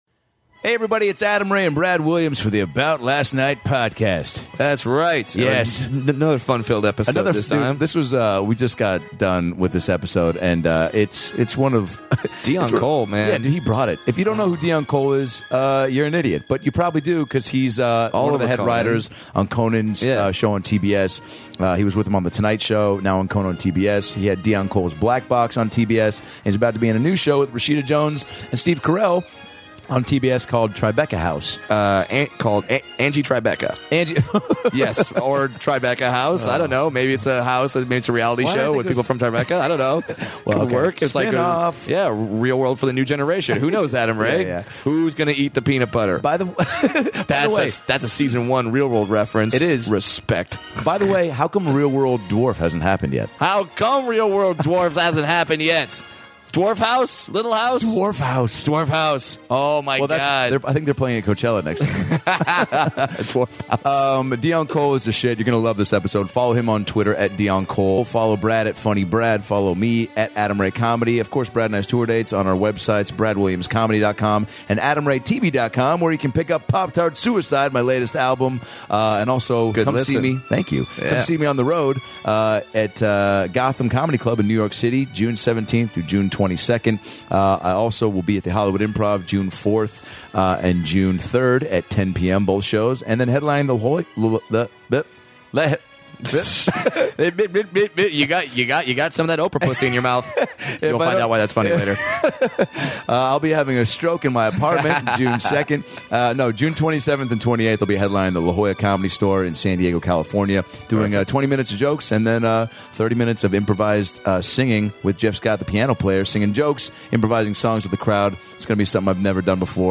CONAN writer & hilarious comedian Deon Cole stops by to talk about starting comedy in Chicago, losing "The Tonight Show," and what Oprah tapings are really like.